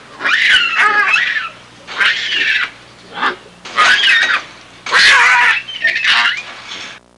Chimps Playing Sound Effect
chimps-playing-1.mp3